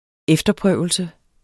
Udtale [ -ˌpʁœˀwəlsə ]